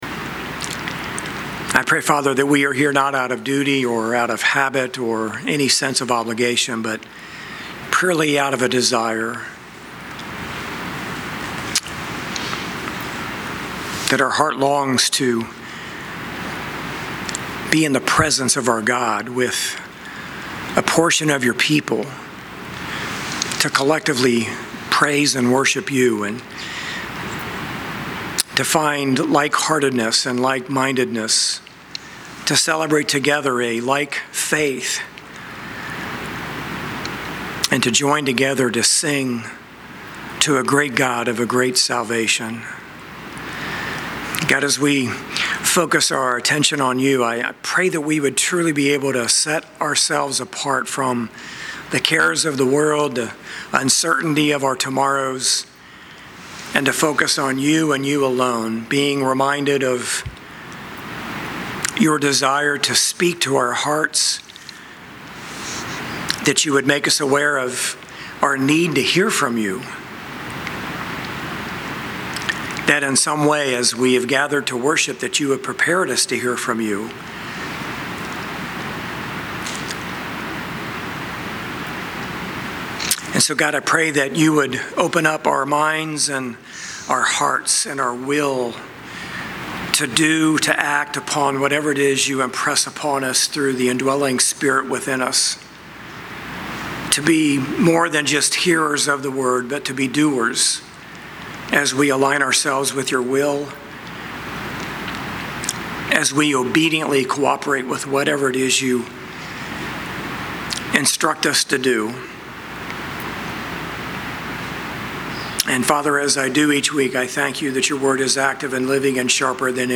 9-7-25-Sermon.mp3